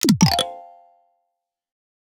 wrong.wav